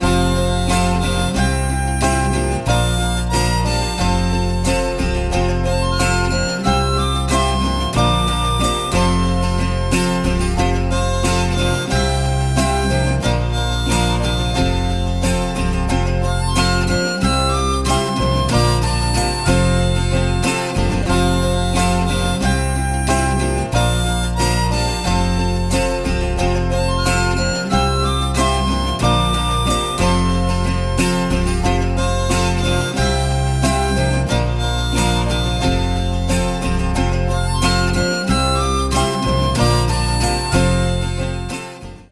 • Качество: 195, Stereo
красивые
спокойные
без слов
инструментальные
губная гармошка